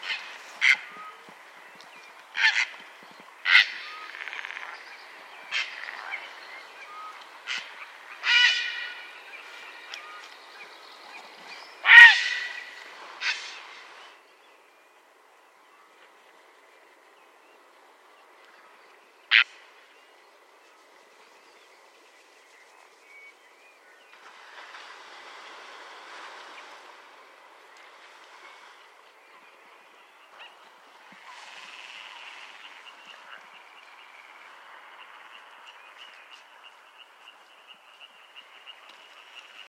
Héron pourpré - Mes zoazos
heron-pourpre-2.mp3